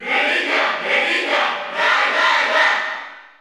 Category: Crowd cheers (SSBU) You cannot overwrite this file.
Greninja_Cheer_Spanish_PAL_SSBU.ogg.mp3